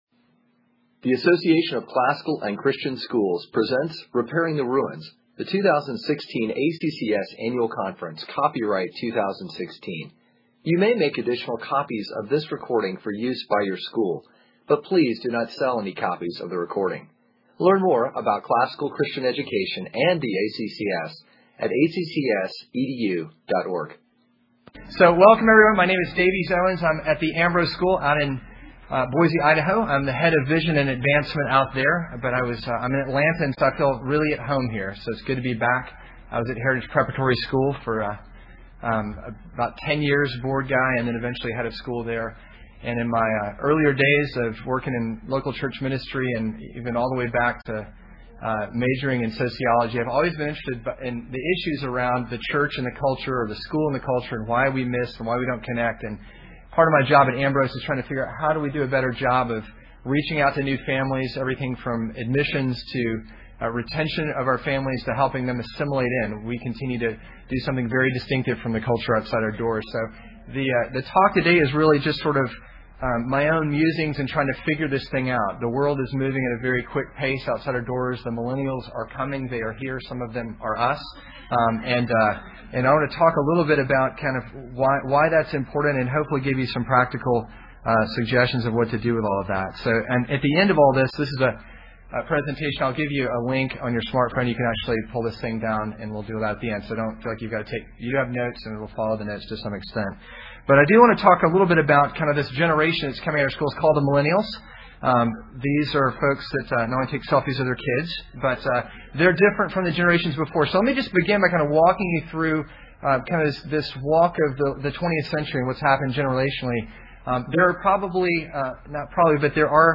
2016 Workshop Talk | 1:00:47 | All Grade Levels, Culture & Faith, Marketing & Growth